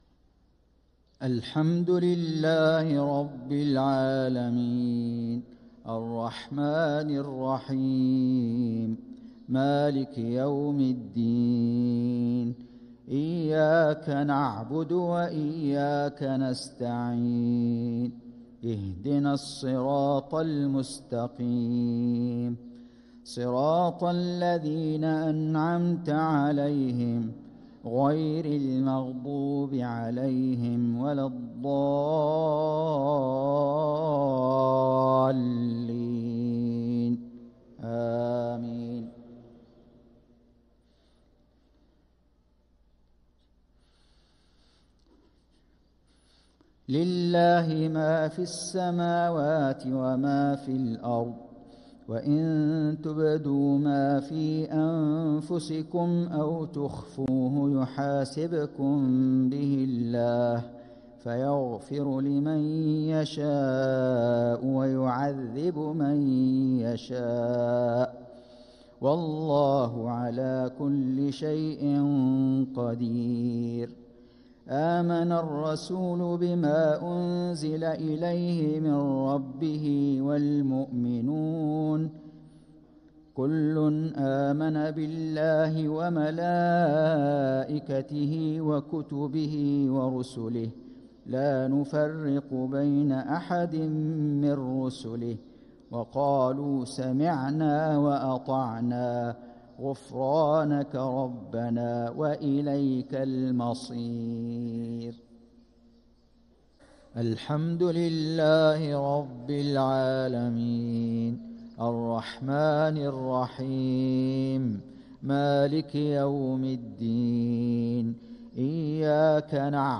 صلاة الجمعة ٨-٨-١٤٤٦هـ | خواتيم سورة البقرة 284-286 | Jumaah prayer from Surah al-Baqarah | 7-2-2025 > 1446 🕋 > الفروض - تلاوات الحرمين